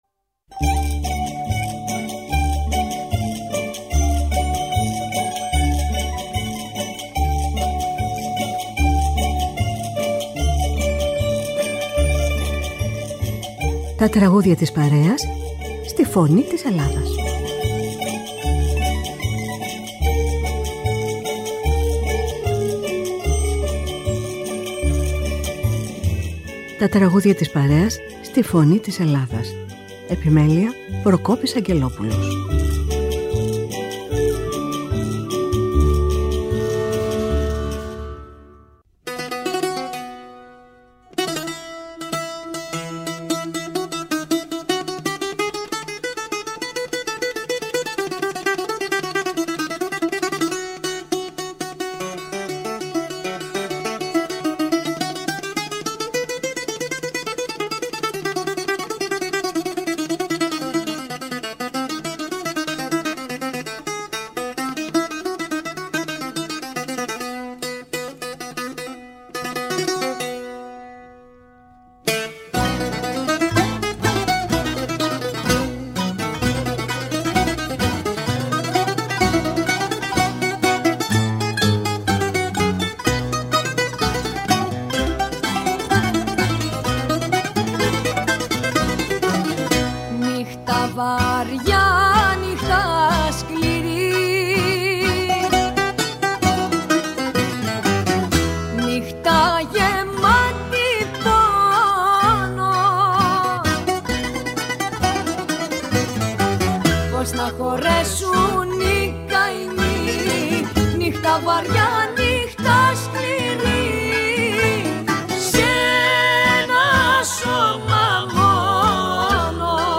Με μουσικές από την Ελλάδα και τον κόσμο.